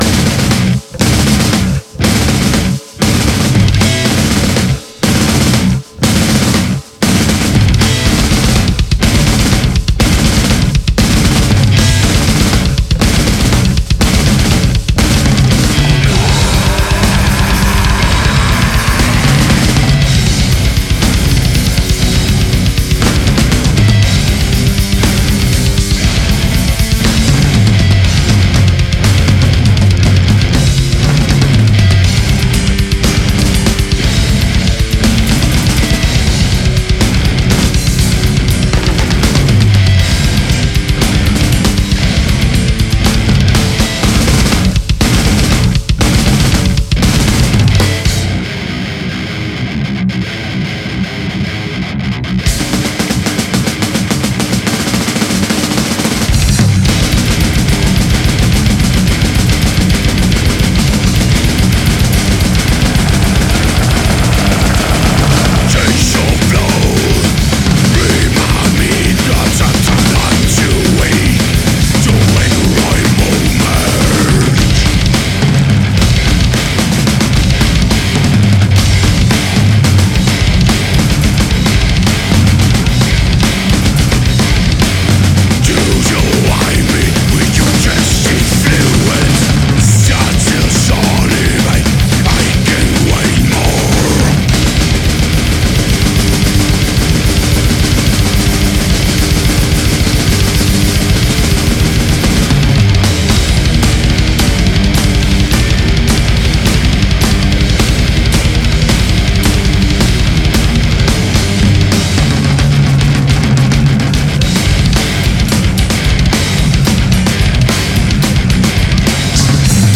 Genre: deathmetal.